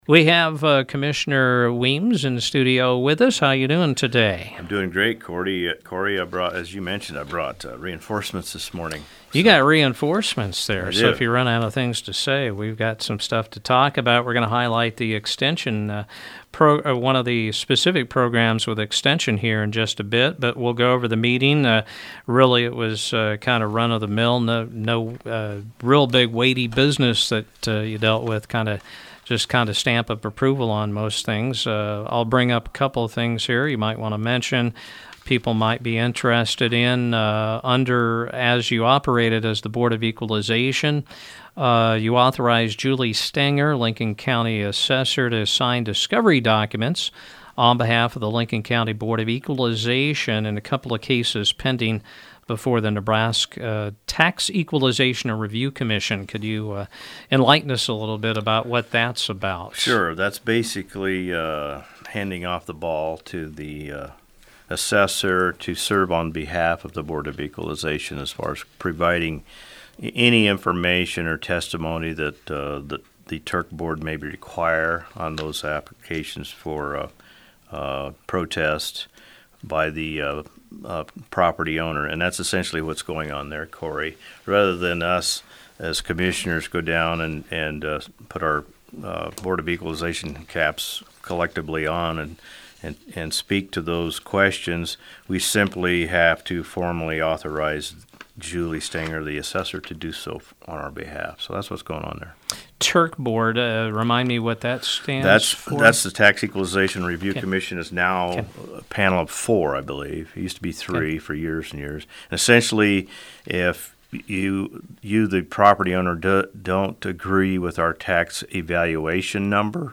Lincoln County Commissioner Kent Weems gave a Board meeting recap, and members of the Lincoln-Logan-McPherson Co Extension appeared in studio on Mugs Tuesday to talk about their OWLS program: